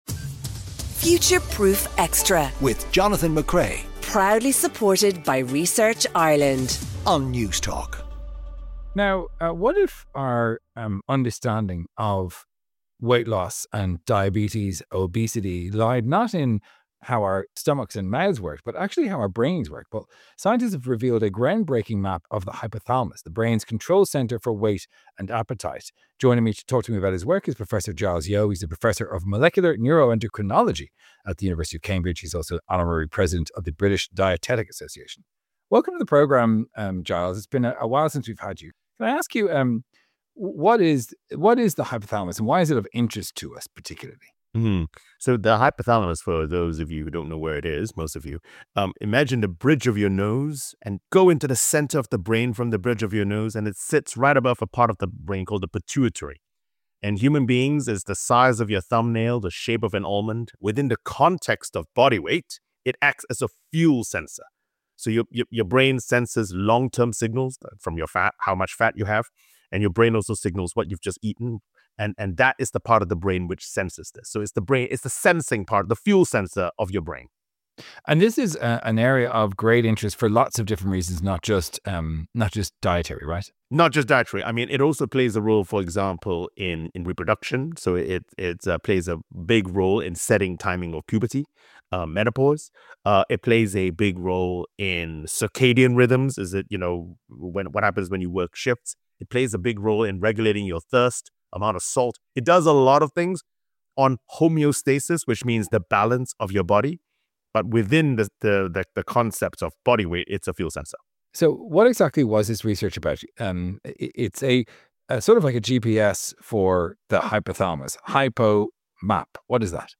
Guest: Professor Giles Yeo